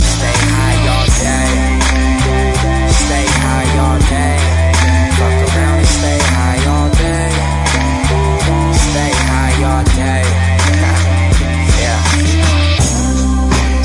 American Rapper